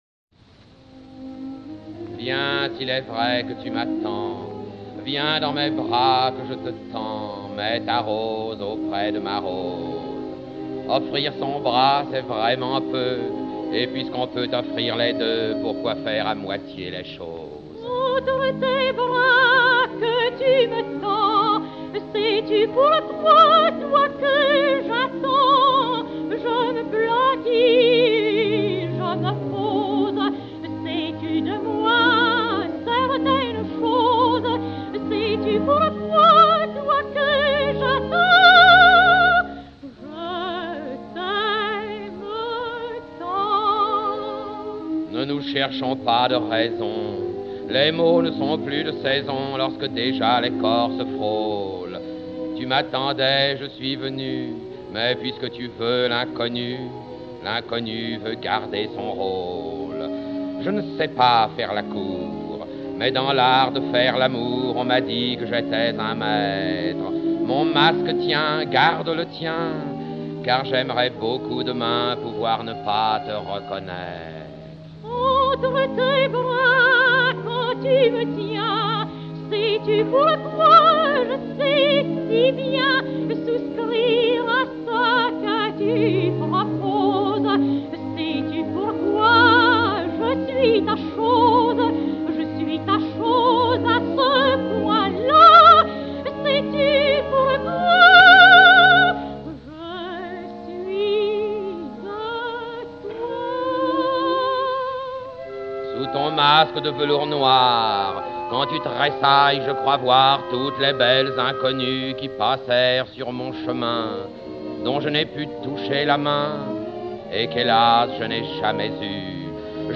enr. à Londres le 12 juillet 1929